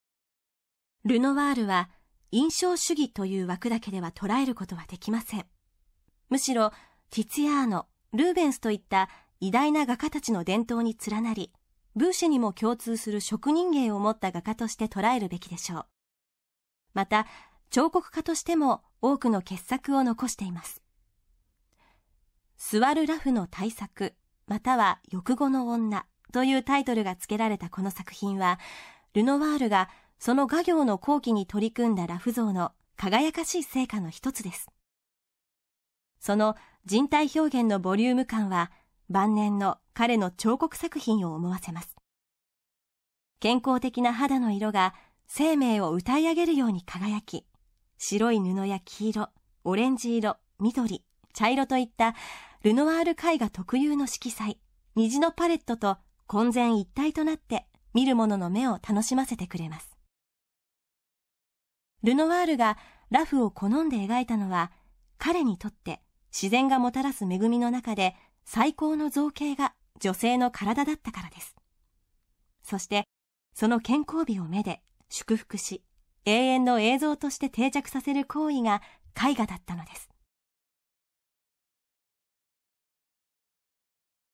作品詳細の音声ガイドは、すべて東京富士美術館の公式ナビゲーターである、本名陽子さんに勤めていただいております。